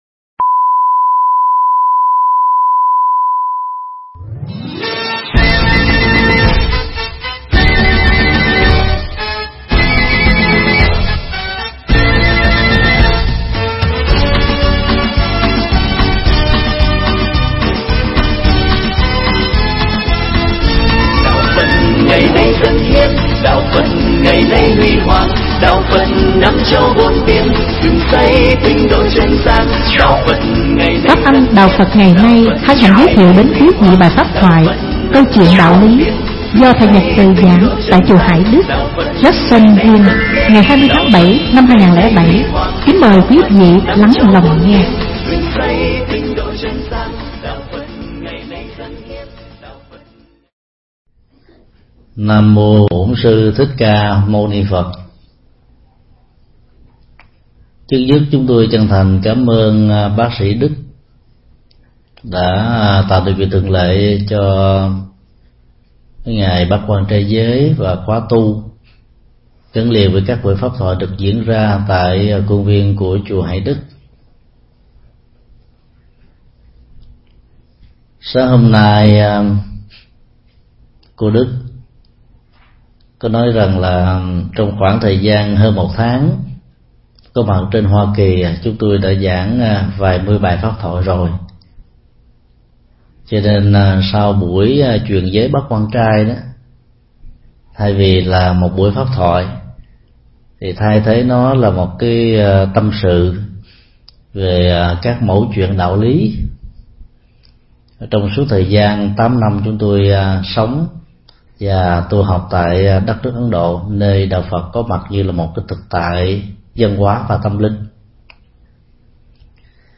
Nghe Pháp âm Câu Chuyện Đạo Lý được thầy Thích Nhật Từ thuyết pháp tại Chùa Hải Đức, Jacksonville, ngày 20 tháng 07 năm 2007.